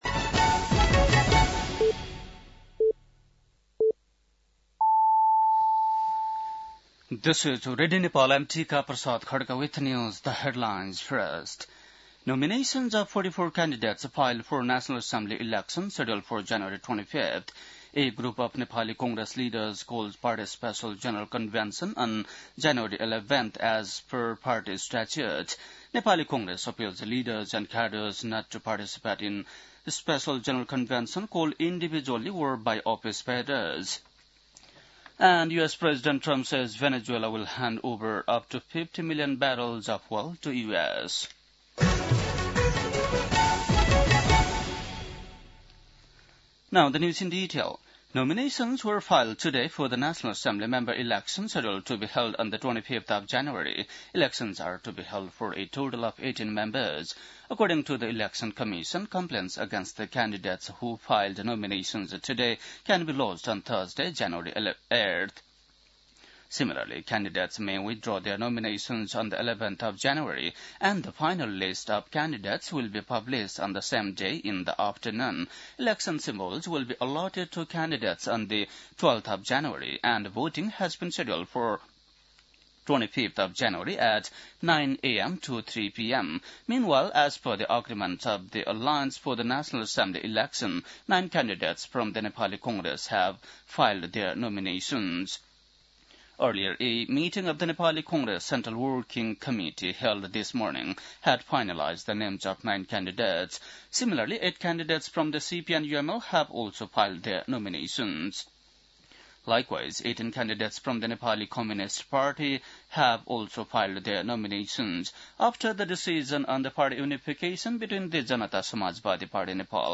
बेलुकी ८ बजेको अङ्ग्रेजी समाचार : २३ पुष , २०८२
8-pm-english-news-9-23.mp3